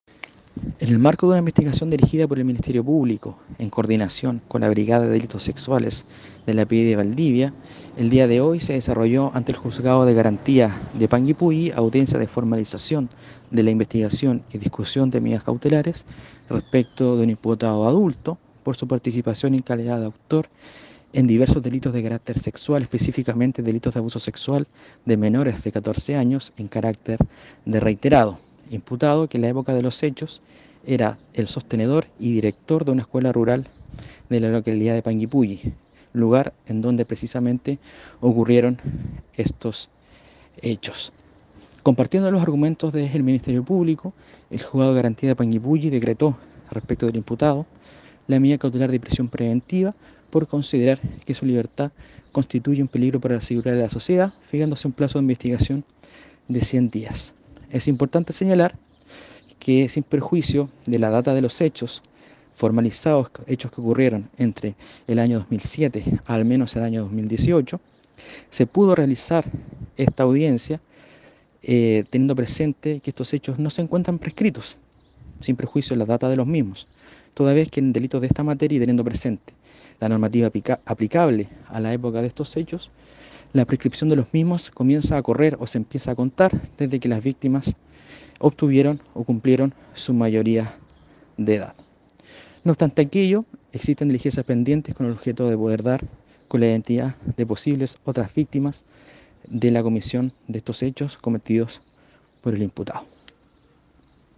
Fiscal subrogante Eduardo Díaz sobre la investigación que dirige la Fiscalía de Los Ríos por estos hechos.